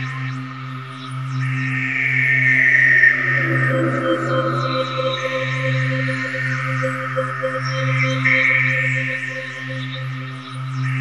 SWIRLING.wav